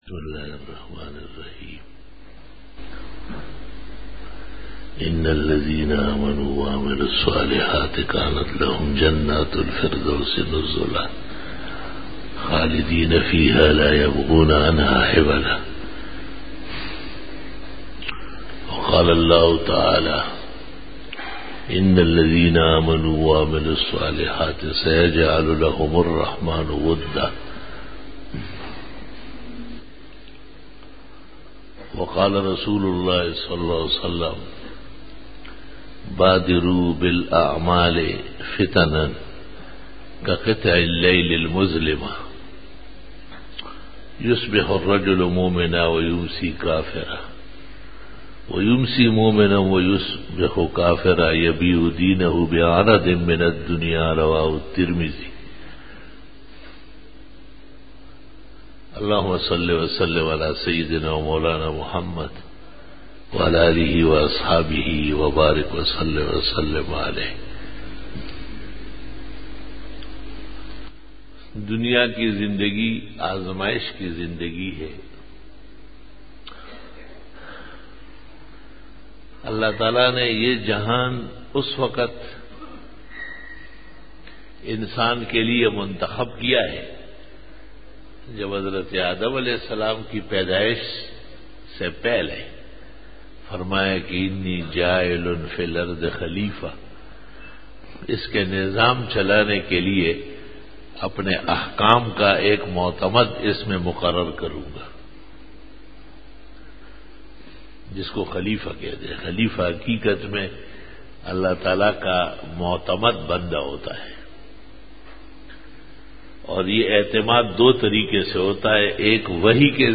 07 Bayan e juma tul mubarak 15-feburary-2013